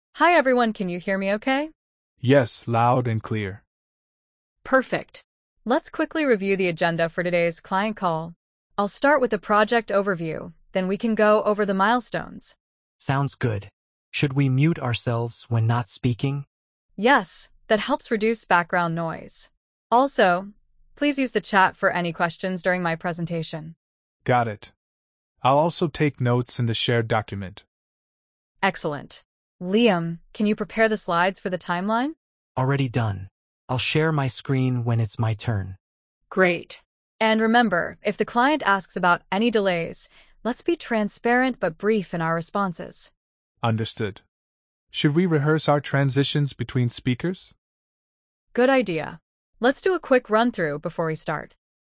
🤝 A team prepares for an important client call.